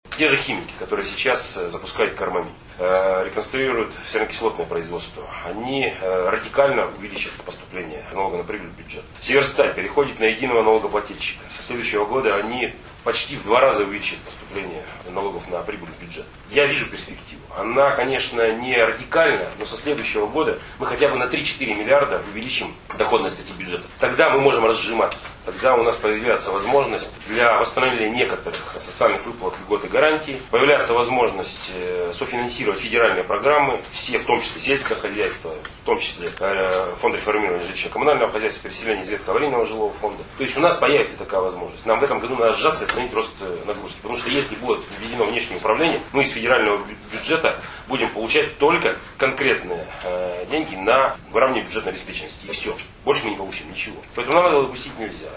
Олег Кувшинников рассказывает о налоговых поступлениях от промышленных предприятий Череповца